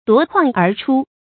夺眶而出 duó kuàng ér chū 成语解释 眶：眼的四周。